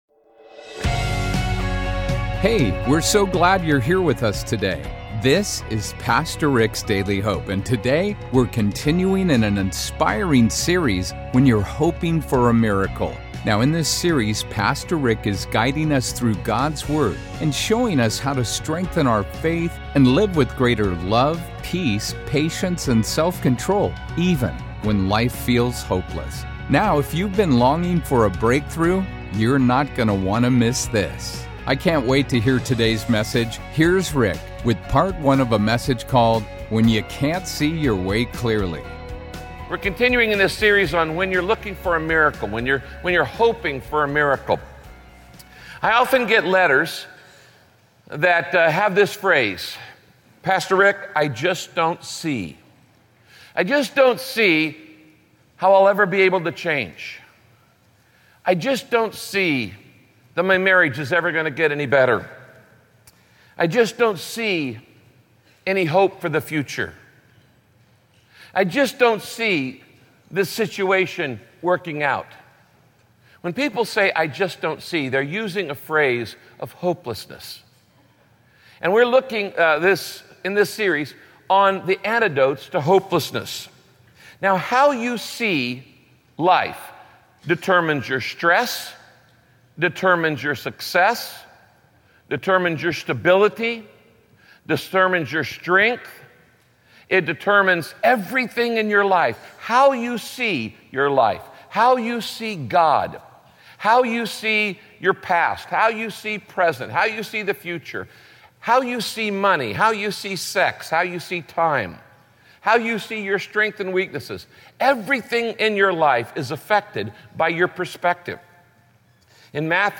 Pride blinds you from what God is doing in your life. In this message, Pastor Rick teaches how humility can help you see God at work in your life.